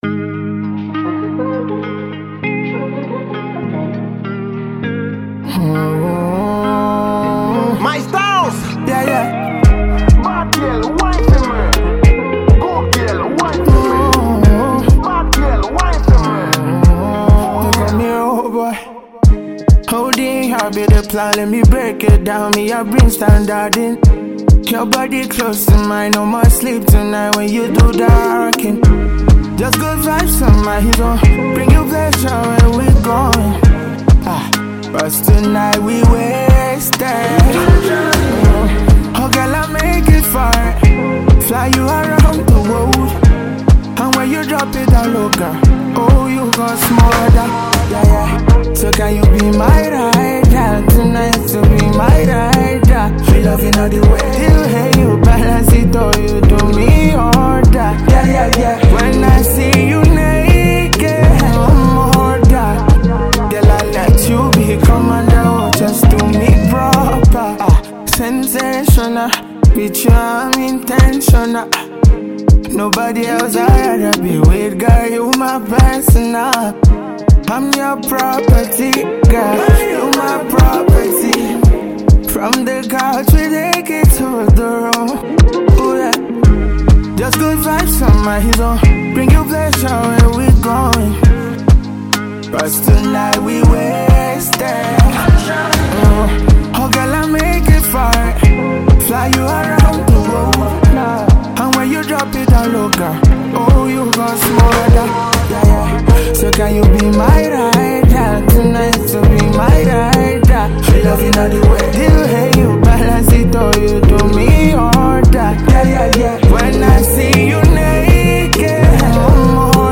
Highly Talented Nigerian afro-pop Singer
serenading voice